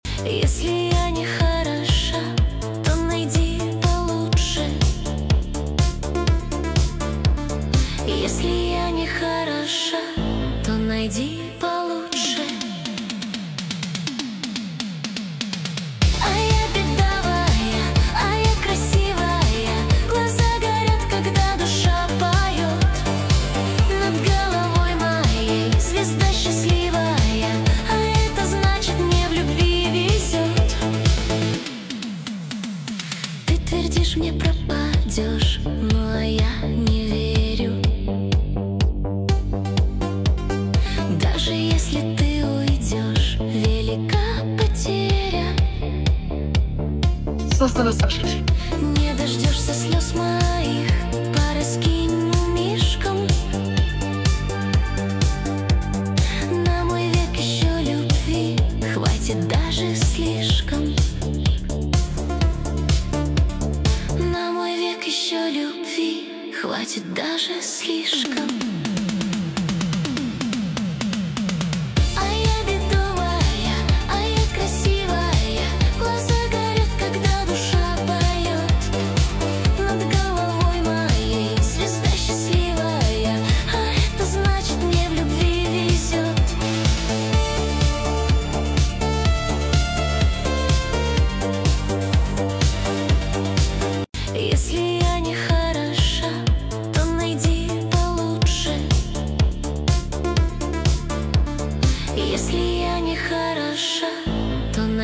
Песня ИИ нейросеть